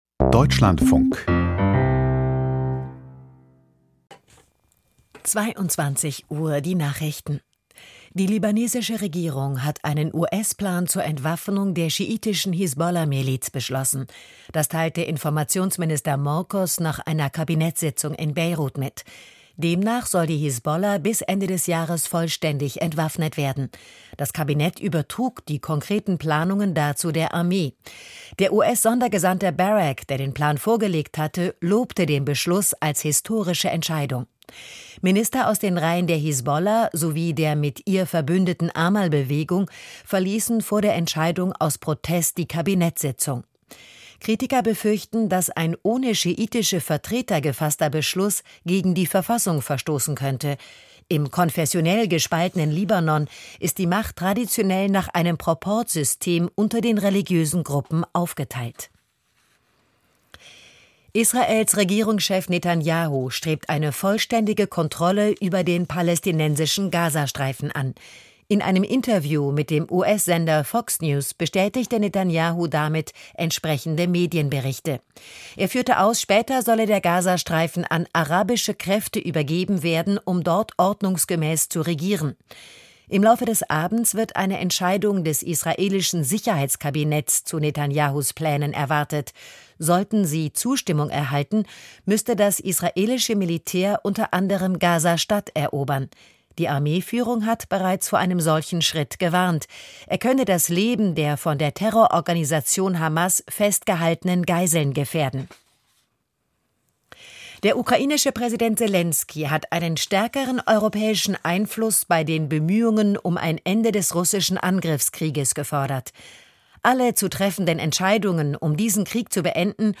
Die Nachrichten vom 07.08.2025, 22:00 Uhr
Aus der Deutschlandfunk-Nachrichtenredaktion.